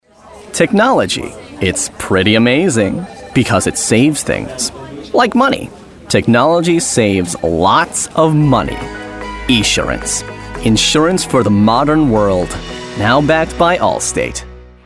Englisch (Amerikanisch)
Natürlich, Freundlich, Warm
Kommerziell